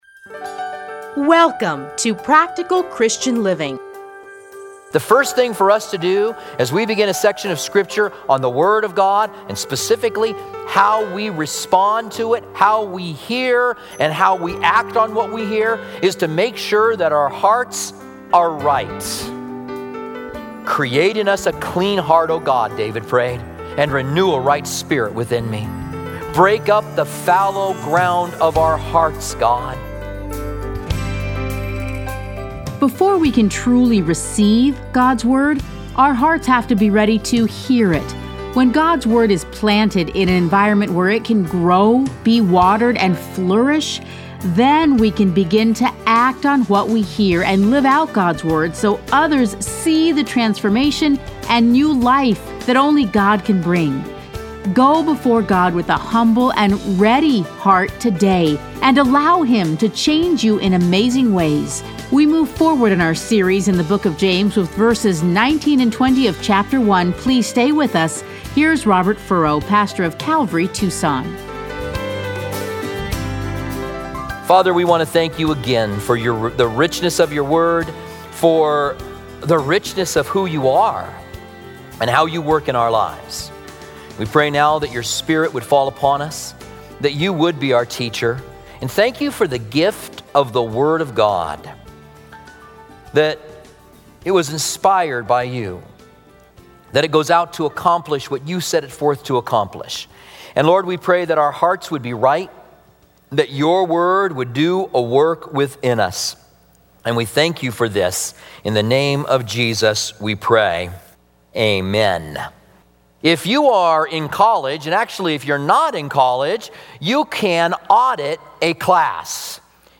Listen here to a teaching from the book of James.